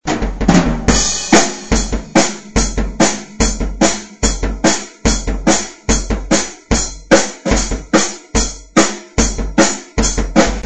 Барабаны, тарелки, палочки и вообще все, чем и на чем вы играете.
малый меня устраивает:)) вот я про бочку ... :oops: звучит как то пластиково:( незнаю что делать могу еще запись кину ну соль проблемы ясна глушу один пластика тока ударный а малый разве что думаю у меня перетянут..
малый наманый!